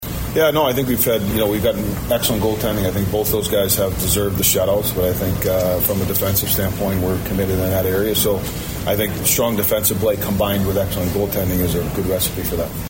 Wild head coach John Hynes on the team as a whole playing well in front of the goaltenders.